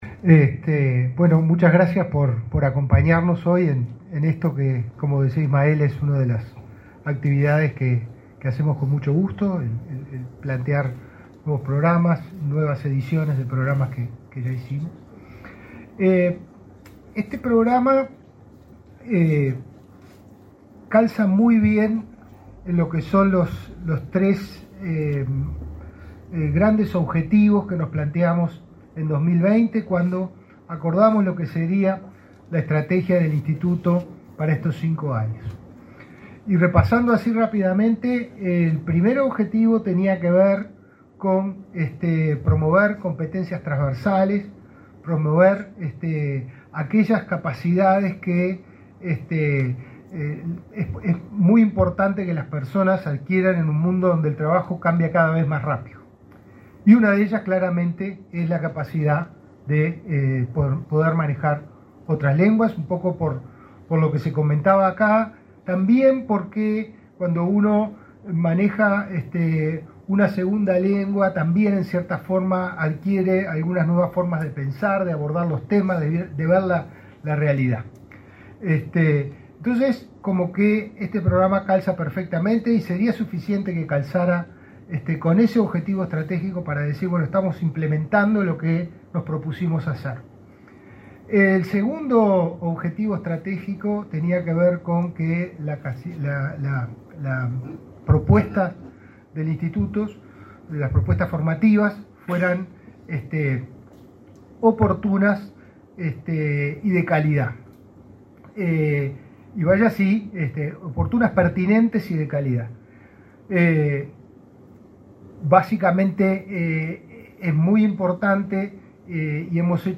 Palabras del director general del Inefop, Pablo Darscht
Este 4 de febrero, se realizó el acto de lanzamiento de la 3.ª edición del programa Idiomas, impulsado por el Instituto Nacional de Empleo y Formación
En el evento, disertó el director general del organismo, Pablo Darscht.